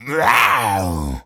tas_devil_cartoon_13.wav